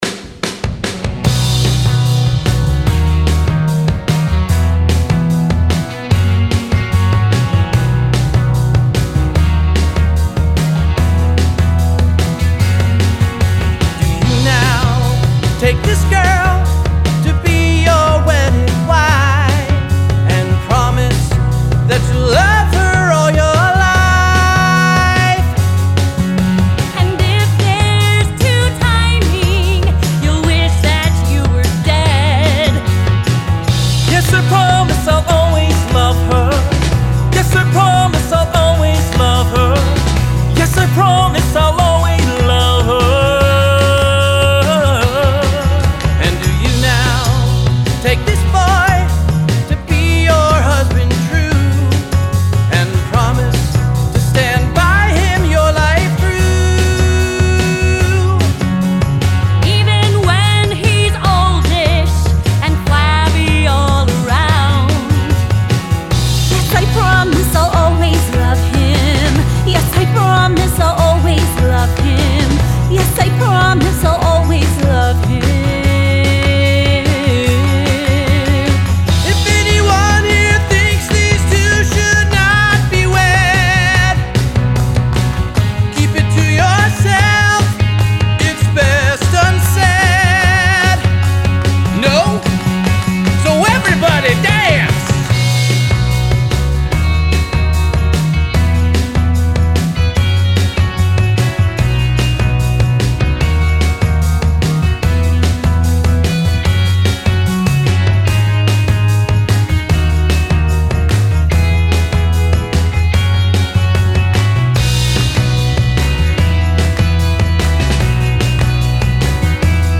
Tom Jones the Musical Demo Recordings
“I’ll Always Love You” is the final song in the show. The feel good, everything ends happily song. It’s Tom and Sophie’s wedding song.
One singer would go in and sing a harmony line.